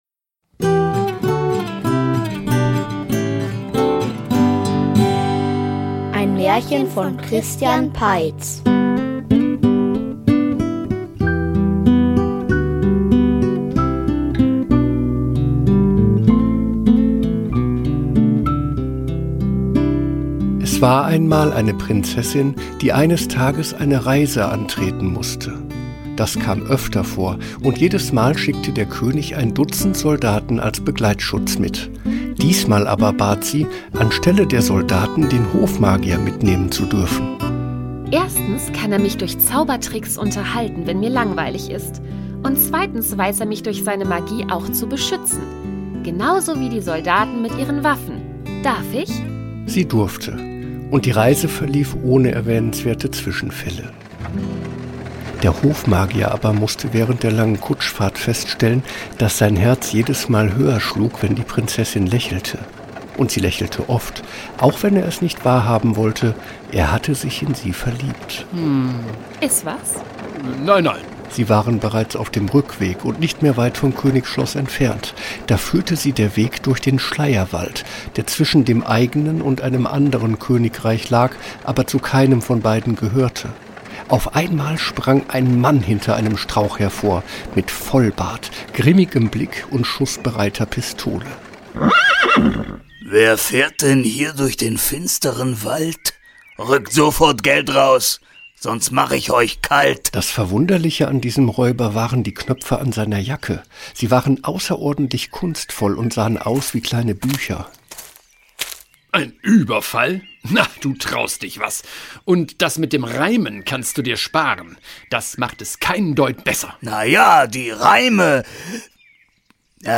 Ein Hörspiel über Zank & Zauberei – nicht nur für Erwachsene